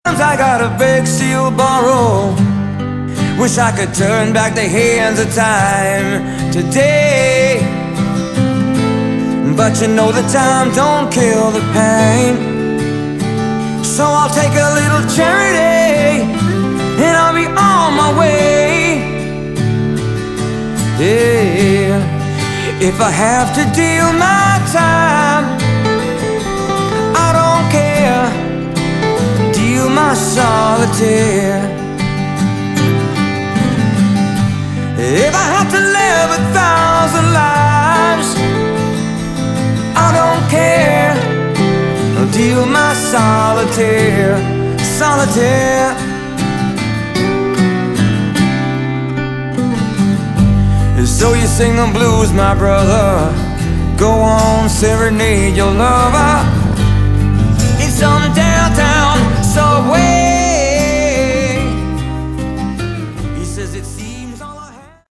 Category: Hard Rock
bass, vocals
guitar
drums